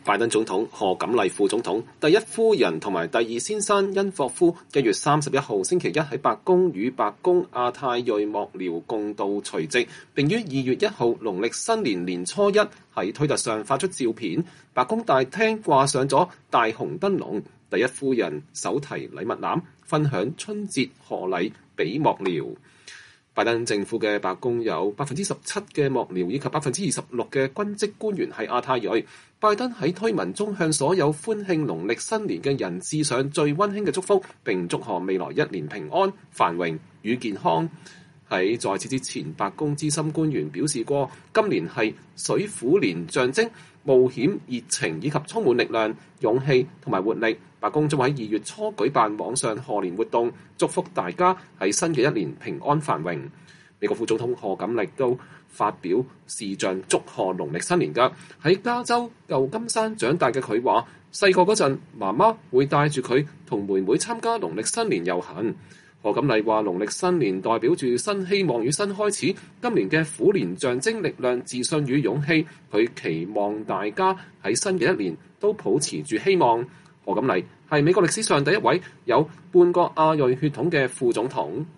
拜登總統、賀錦麗副總統祝賀農曆新年